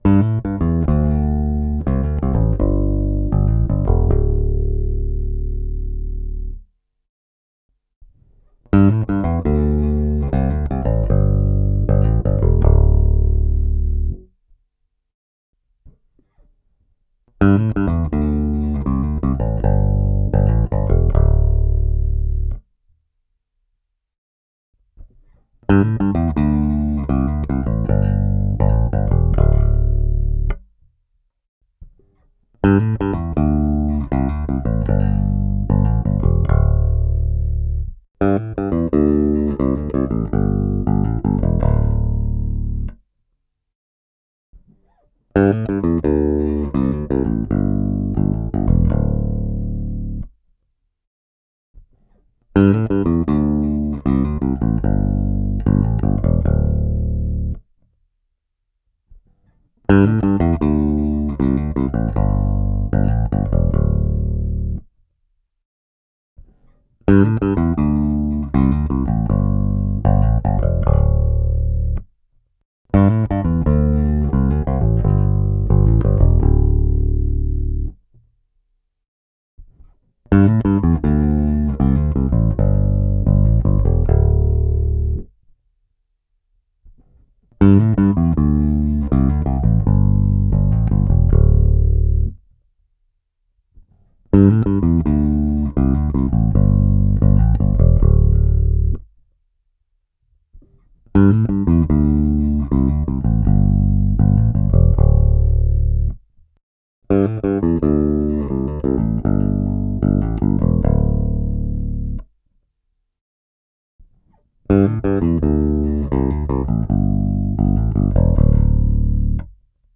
V7 über Focusrite in Garageband DI ohne jeglichen EQ. 1.Durchgang: Neck auf Master und dann jeweils um ca 20% den Bridge PU dazu gemischt 2.Durchgang: Bridge auf Master und dann jeweils um ca 20% den Neck PU dazu gemischt 3+4 das Gleiche nochmal in seriell Anhänge V7 X-Blend II.mp3 4,3 MB